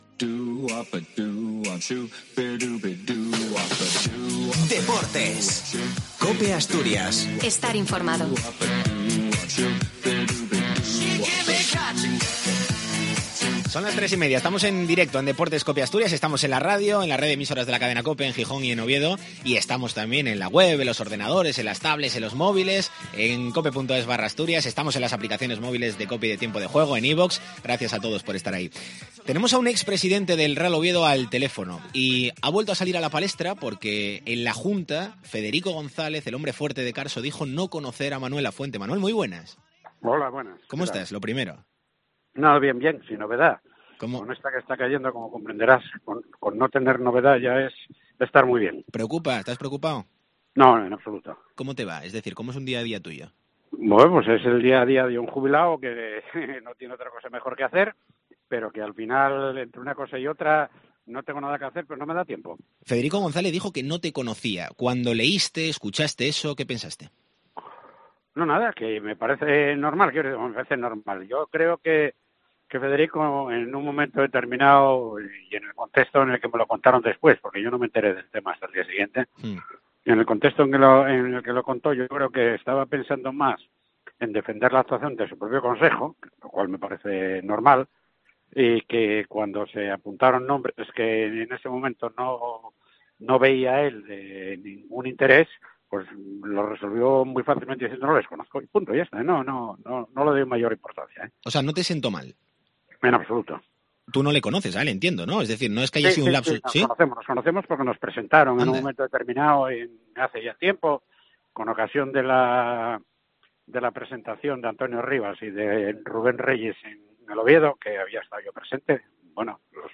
Deportes COPE Asturias Entrevista